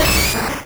Cri de Machoc dans Pokémon Rouge et Bleu.